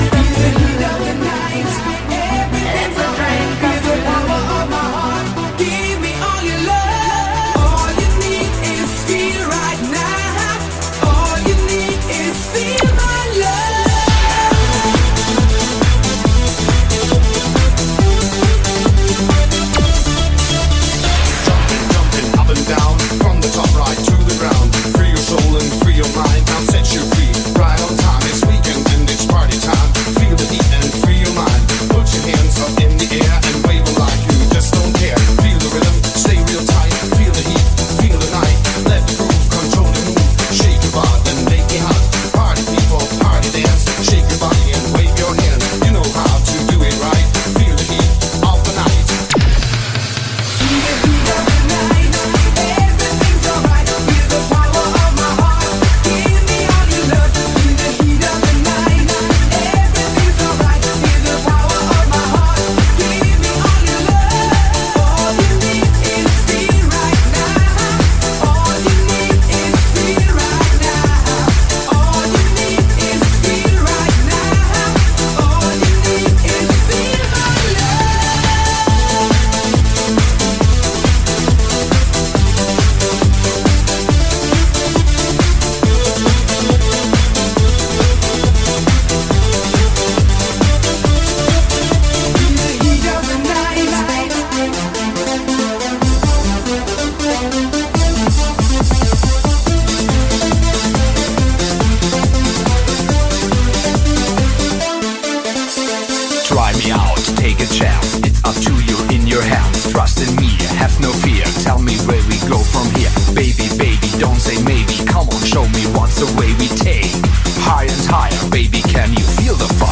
Лучшие танцевальные _90 х ___VOL 4 __